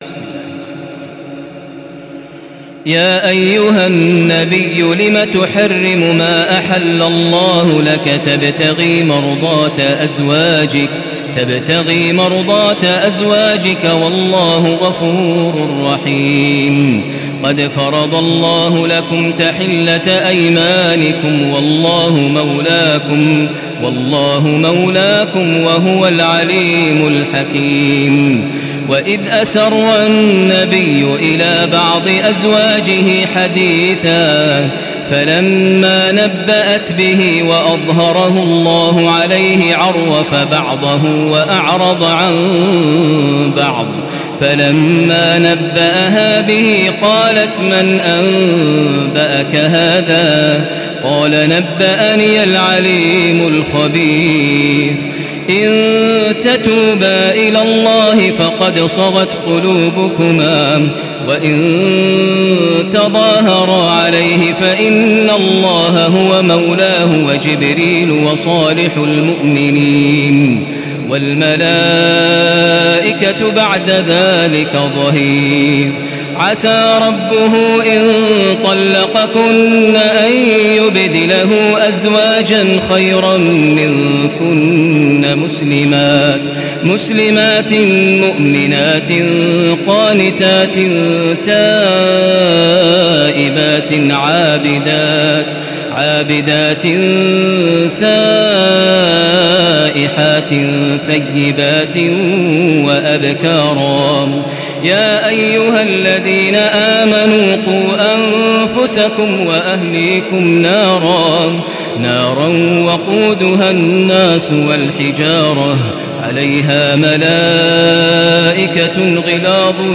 Makkah Taraweeh 1435
Murattal